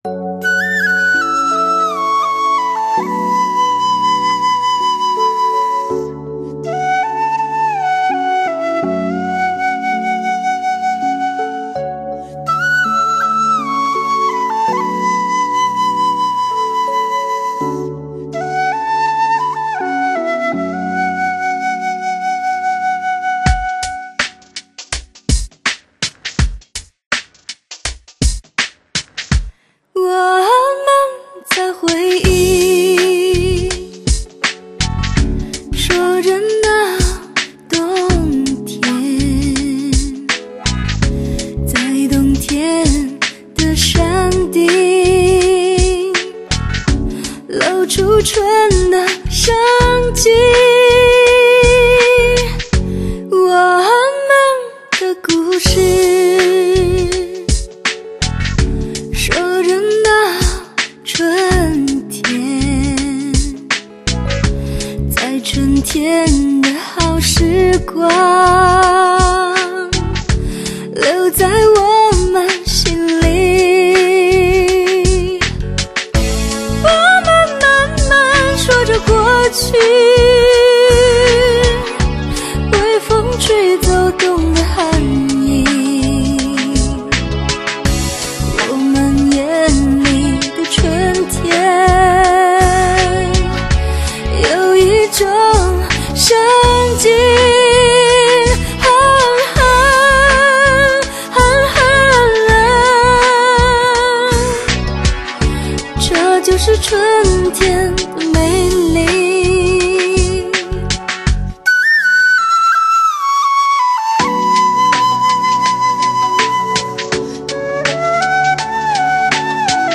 远赴大江南北，深入神州大地，自然无造作的录音，真实原音重现。
倾听繁华世界里单纯的声音  最清澈动人的音乐旋律